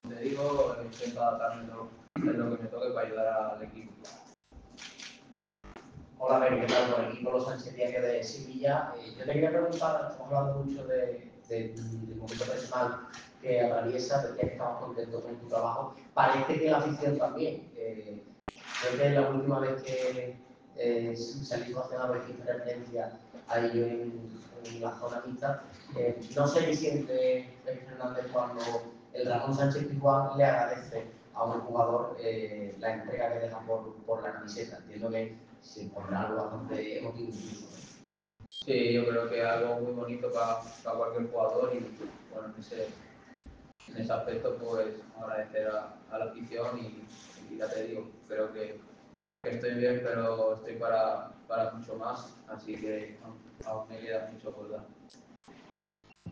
Gerard Fernández «Peque» volvió a situarse en el foco mediático tras comparecer en la sala de prensa del Estadio Jesús Navas.